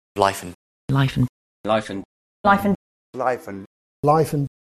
By contrast, here are speakers saying life and, from the phrase life and death:
You should be able to hear that the instances of and are /ən/, while the instances of on retain their full vowel.